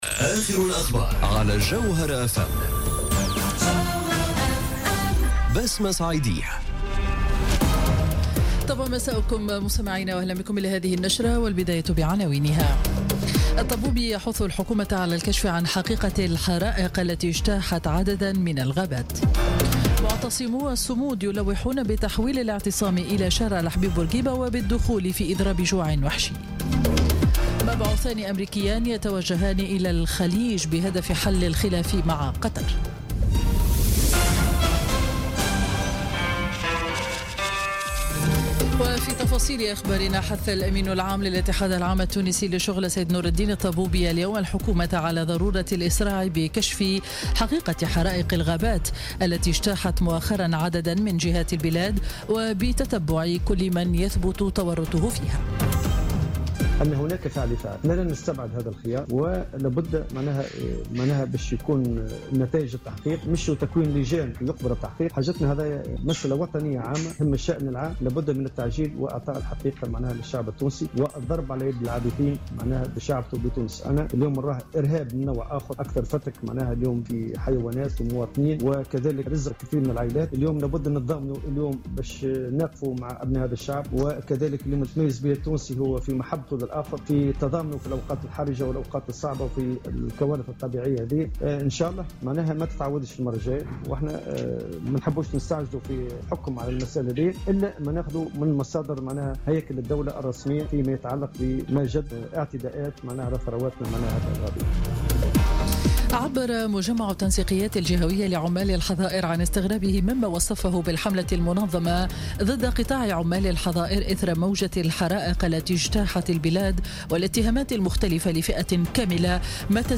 Journal Info 19h00 du lundi 7 août 2017